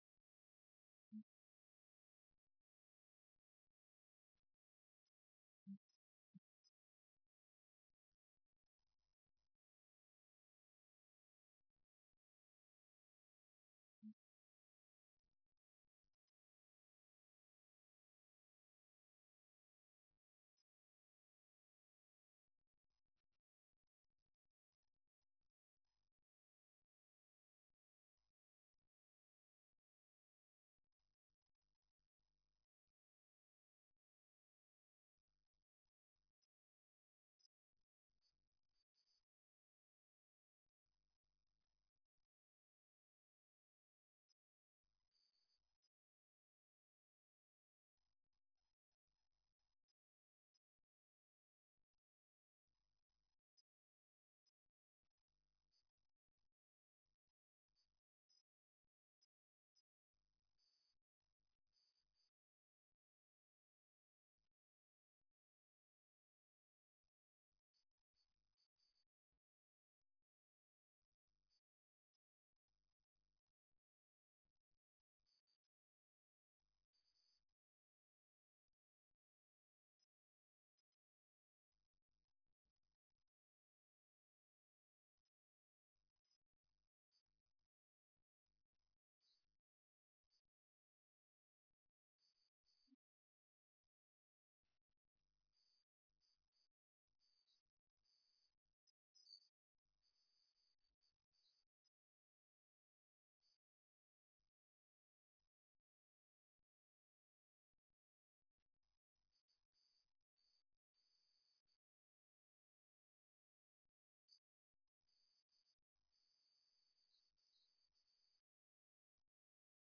بیانات در دیدار پرشور هزاران نفر از دانشجویان و دانش آموزان